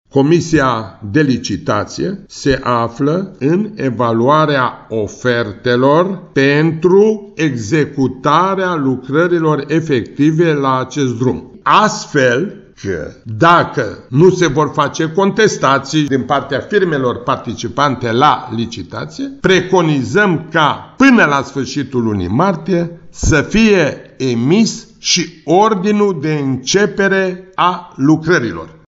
Vicepreședintele Consiliului Județean Timiș, Nicolae Oprea, spune că în martie ar urma să fie desemnat câștigătorul licitației, iar dacă nu vor fi contestații șantierul va fi deschis imediat.